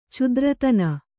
♪ cudratana